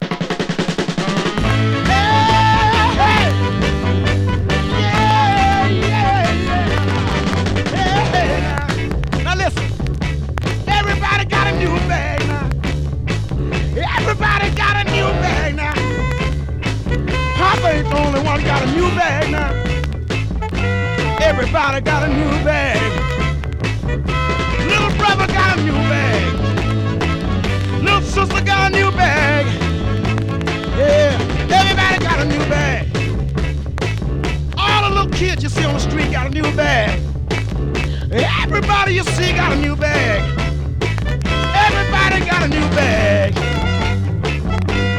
Rhythm & Blues, Funk, Soul　USA　12inchレコード　33rpm　Mono